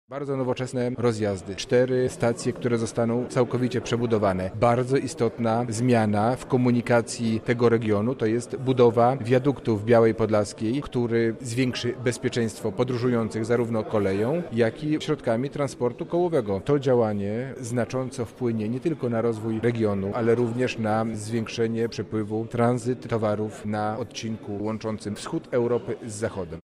Jest to bardzo znaczna inwestycja infrastrukturalna powodująca rewolucję na tym odcinku – mówi Robert Gmitruczuk, Wicewojewoda Lubelski.